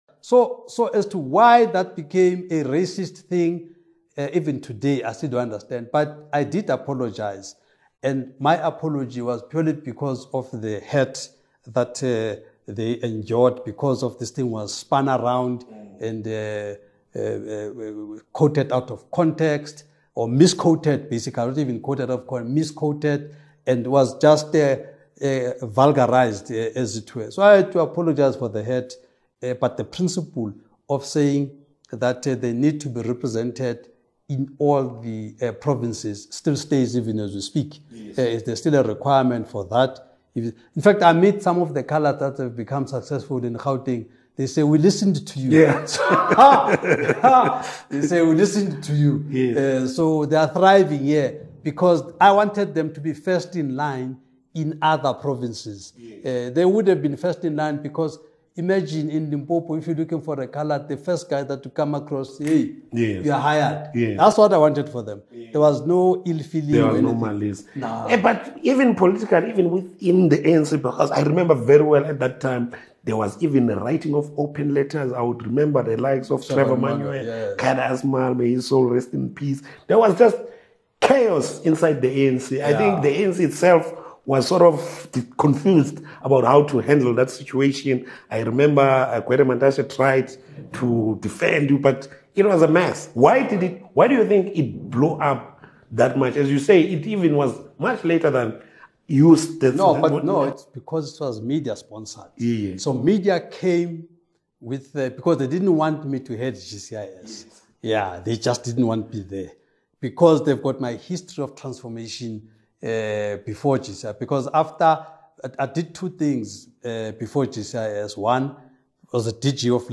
Mzwanele Manyi Opens Up on Zondo Report, ANC, EFF, and His Role as MK Party Chief Whip In this exclusive interview, Mzwanele Manyi shares his insights on the Zondo Commission report, his views on the ANC and EFF, and the key responsibilities he holds as the Chief Whip of the MK Party. Join us for a candid discussion on South African politics, accountability, and the future of leadership in the country.